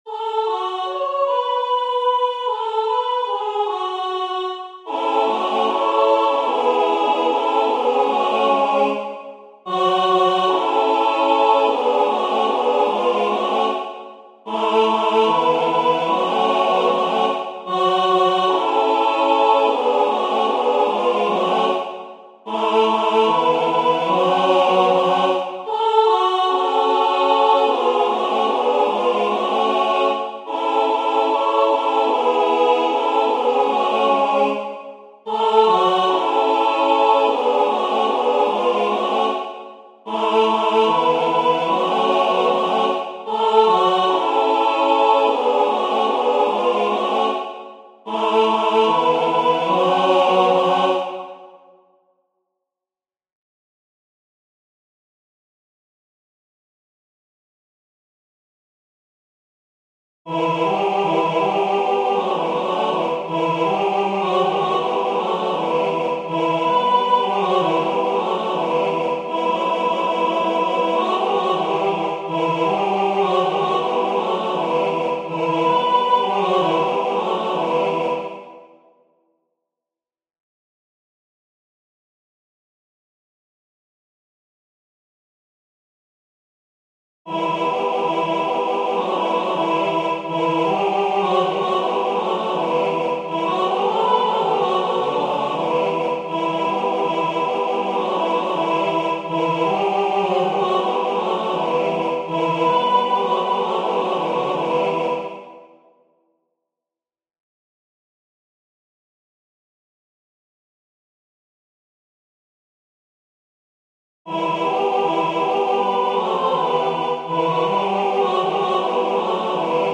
Opracowanie na chór SATB.